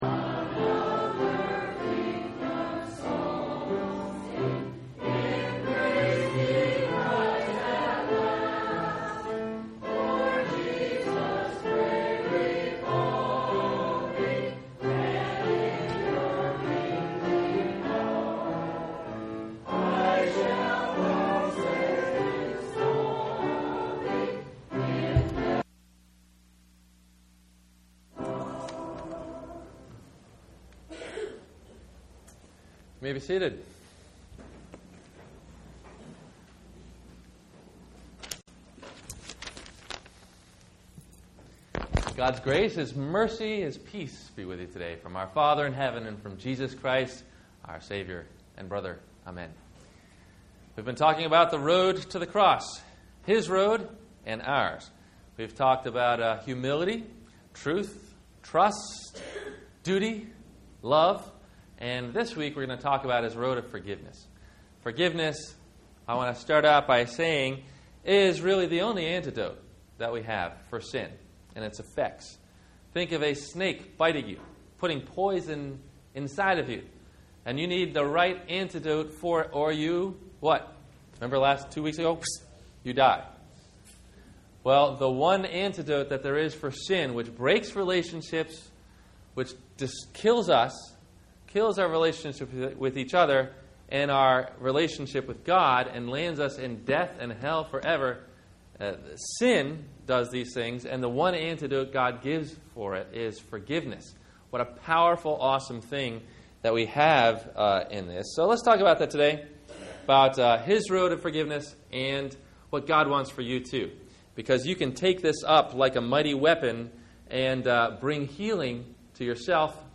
The Road of Forgiveness – Wed. Lent week 5 – Sermon – April 01 2009